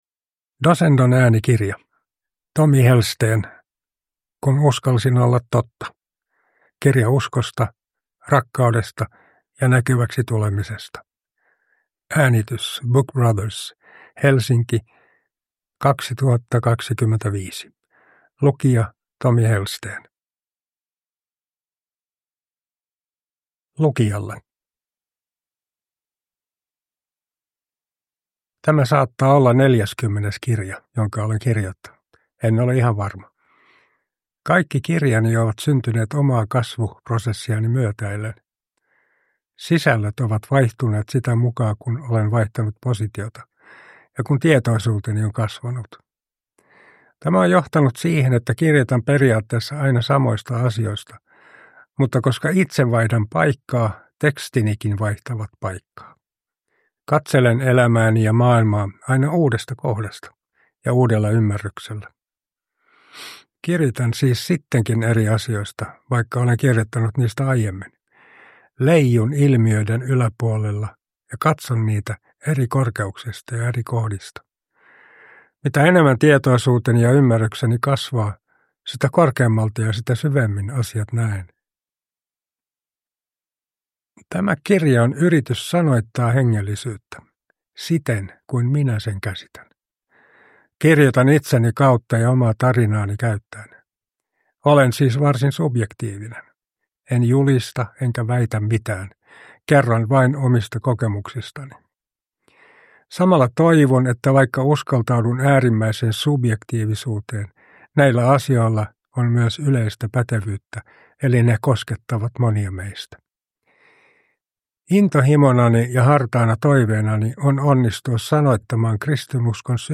Kun uskalsin olla totta – Ljudbok
Uppläsare: Tommy Hellsten